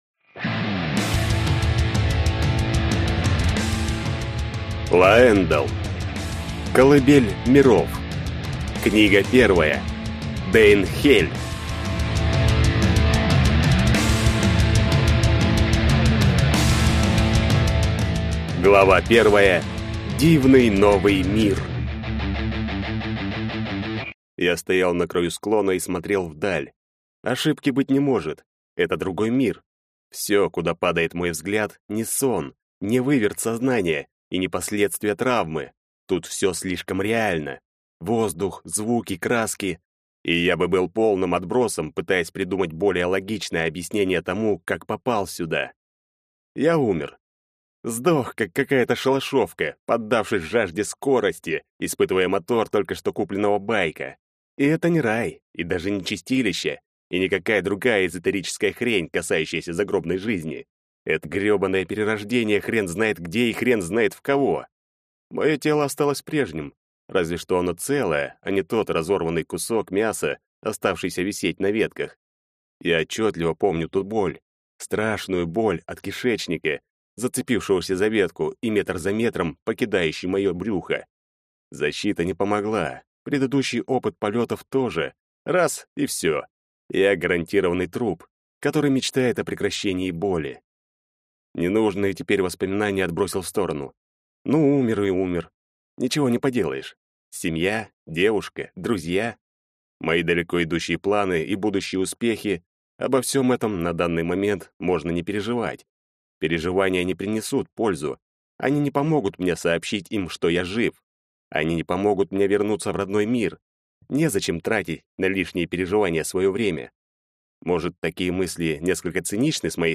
Аудиокнига Дэйн'хель | Библиотека аудиокниг
Прослушать и бесплатно скачать фрагмент аудиокниги